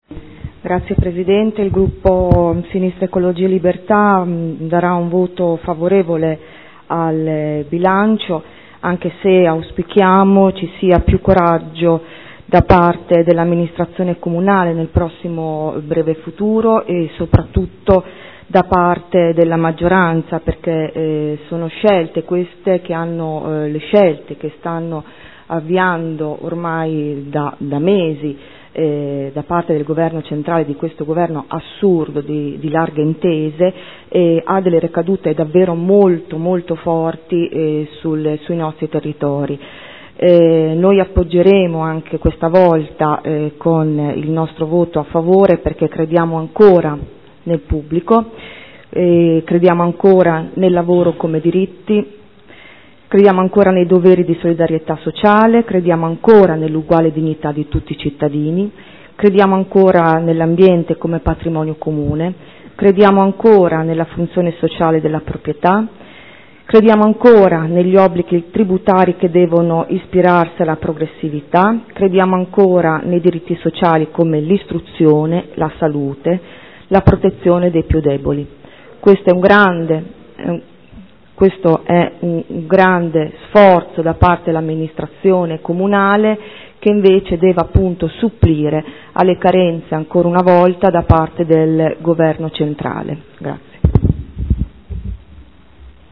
Seduta del 26/09/2012 Dichiarazione di voto. Bilancio di Previsione 2013 – Bilancio Pluriennale 2013-2015 – Programma triennale dei lavori pubblici 2013-2015 – Stato di attuazione dei programmi e verifica degli equilibri di Bilancio – Variazione di bilancio n. 1